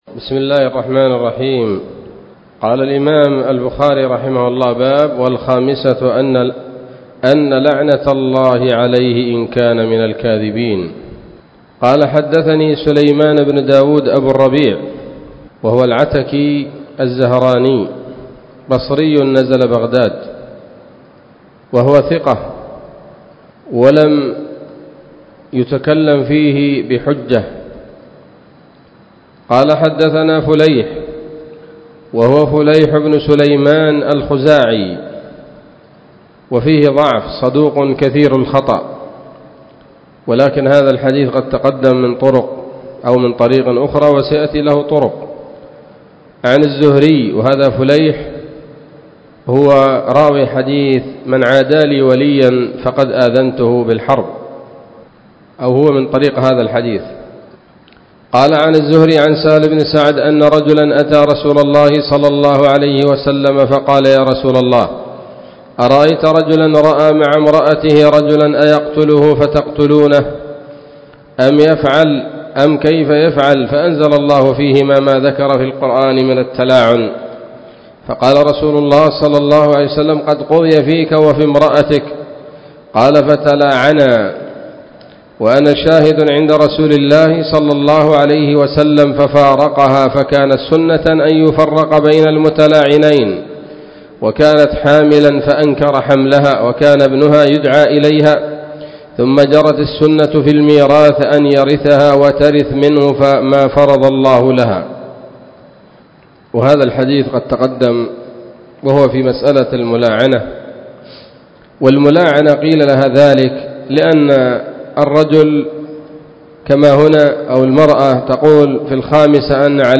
الدروس العلمية